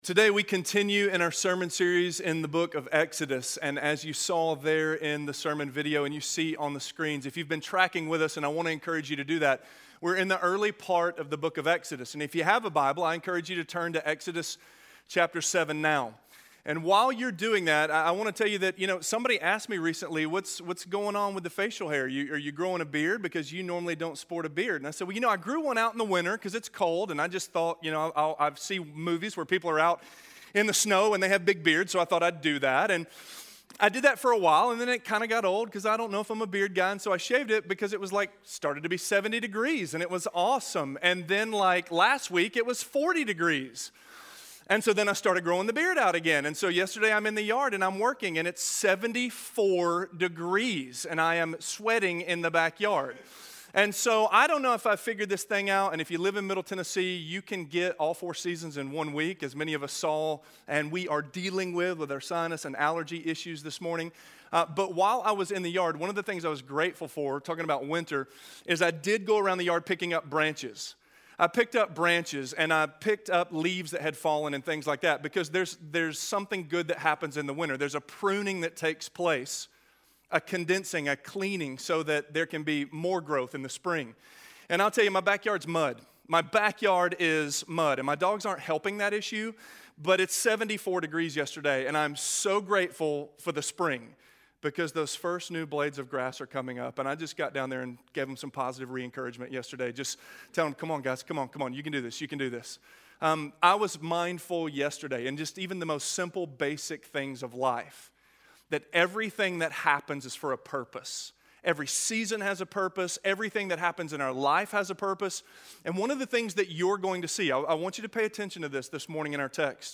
The Anticipation of Evacuation: Plagues - Sermon - Avenue South